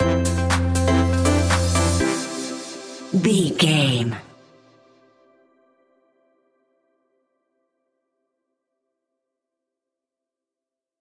Ionian/Major
groovy
dreamy
smooth
drum machine
synthesiser
funky house
upbeat
funky guitar
synth bass